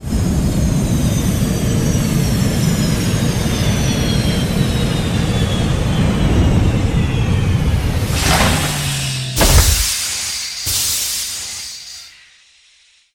landing1.ogg